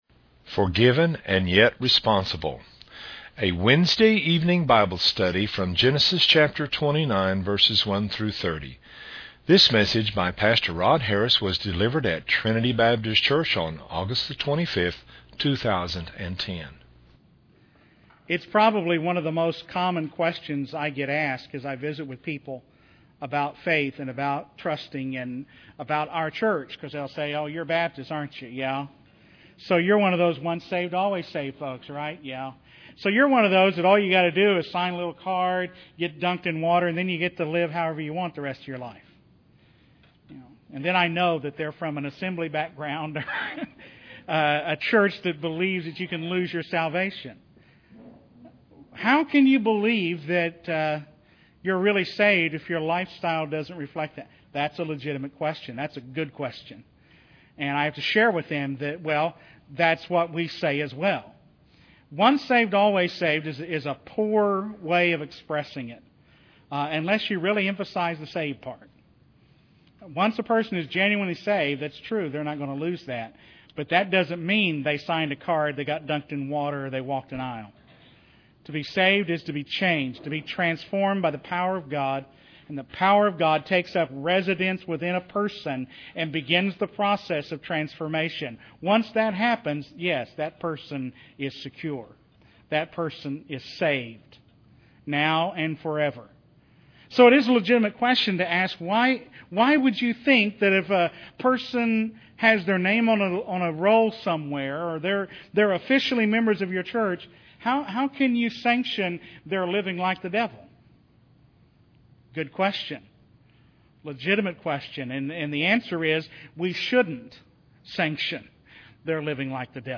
A Wednesday-evening Bible Study from Genesis 29:1-30.